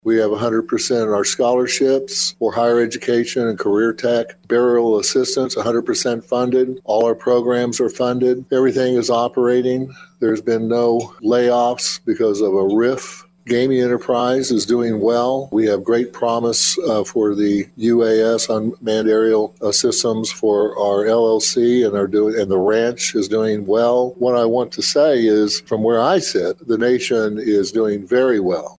Chief Standing Bear Gives a State of the Nation Speech
On Saturday afternoon, Osage Nation Principal Chief Geoffrey Standing Bear gave a State of the Nation speech.